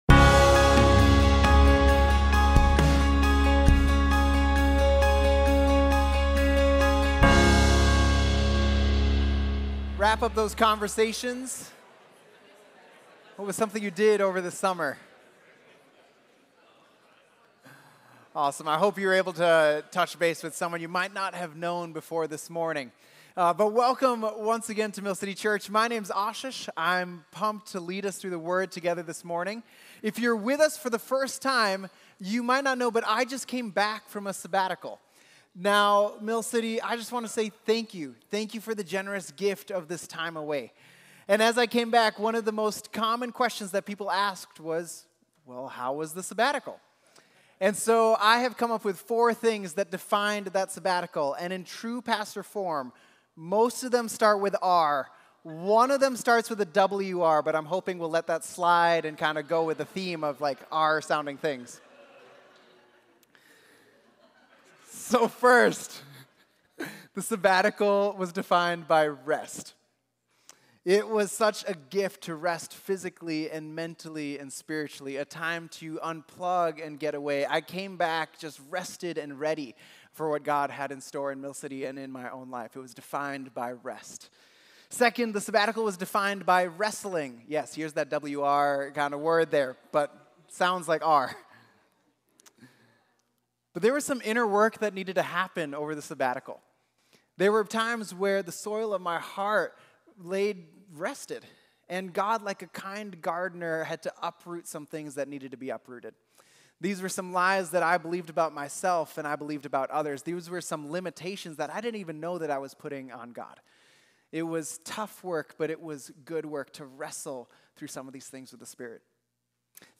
Mill City Church Sermons Authority in the Spirit: God Does Immeasurably More Aug 19 2024 | 00:37:46 1x 00:00 / 00:37:46 Subscribe Share RSS Feed Share Link Embed